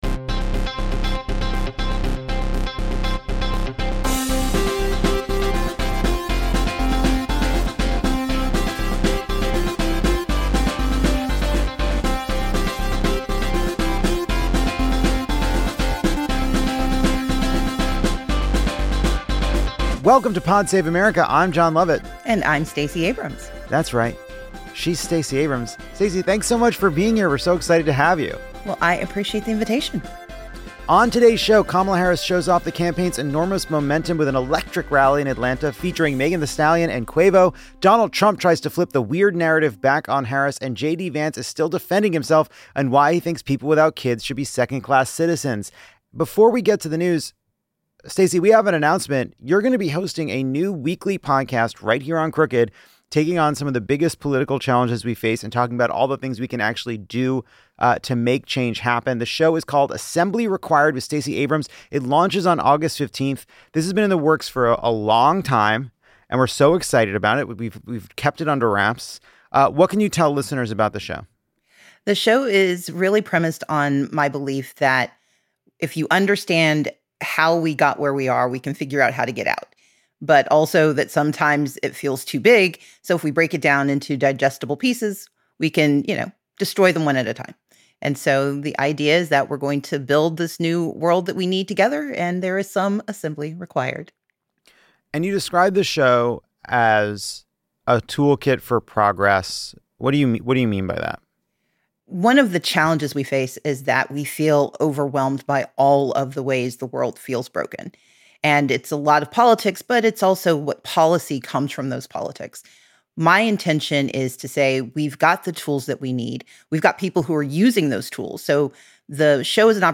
Jon Lovett and guest host Stacey Abrams react to the train wreck in real time. Stacey describes the scene at Kamala Harris's big rally in Atlanta on Tuesday and offers her takes on the JD Vance disaster and how Harris should defend herself from right-wing attacks.